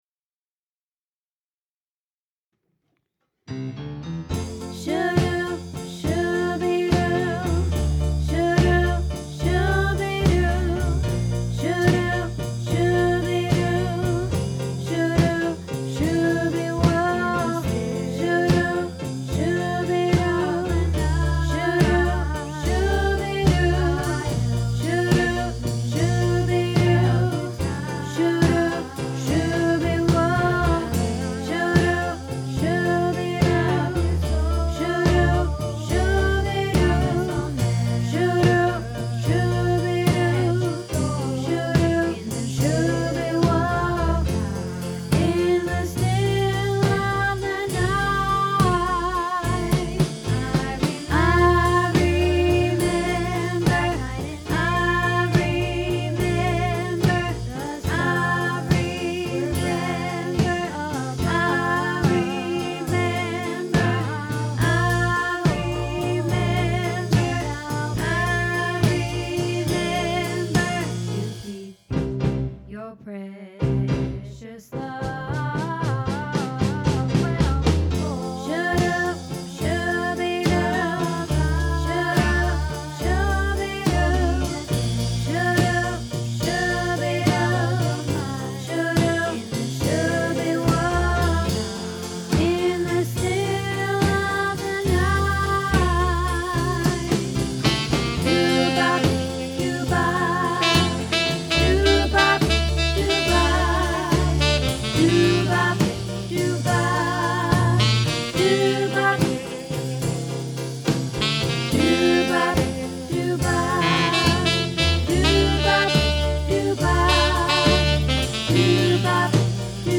In the Still of the Night - Soprano